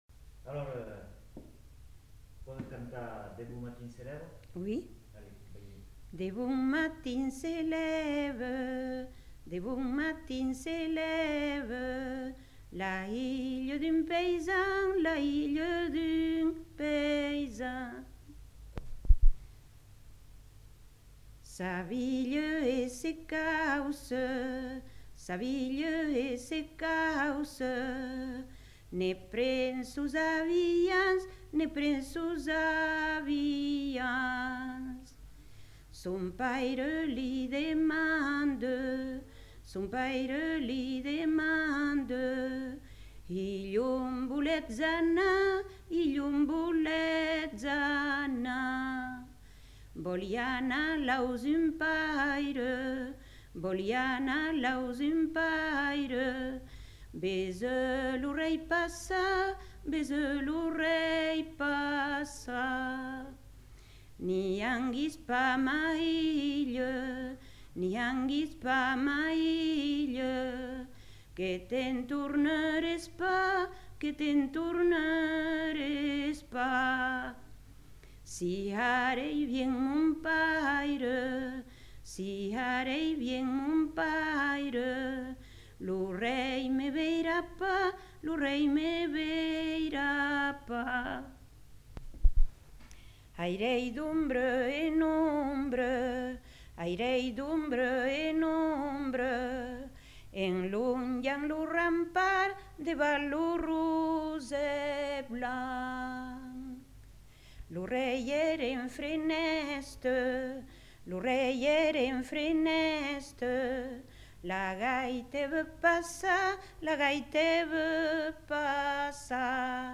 Aire culturelle : Marmandais gascon
Genre : chant
Effectif : 1
Type de voix : voix de femme
Production du son : chanté
Notes consultables : Chant suivi de commentaires.